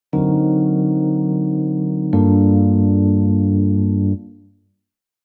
This example features our D7(b9)(b13) resolving to a G-9.
D7 b9 b13 to G-9
D7-b9-b13-Example.mp3